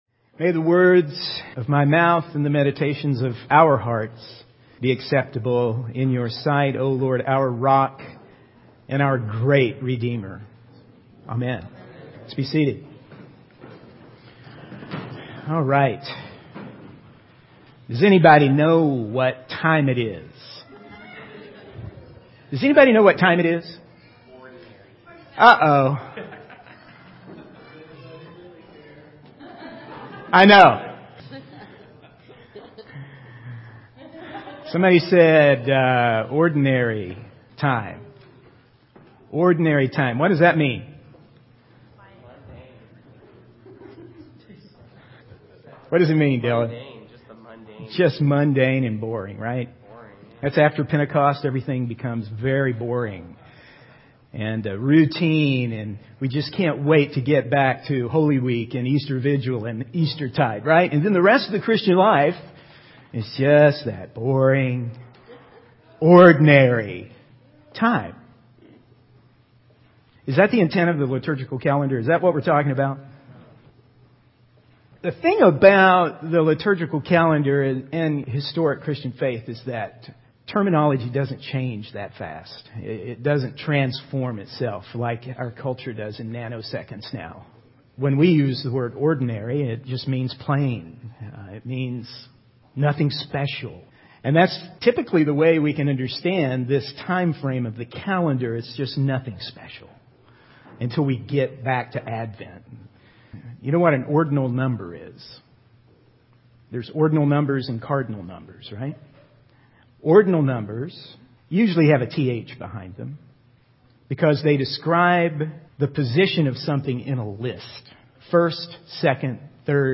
In this sermon, the speaker emphasizes the power of God and the messiness of our lives. He highlights that despite the challenges and difficulties we face, we should not lose heart because we have been given the grace and forgiveness of God.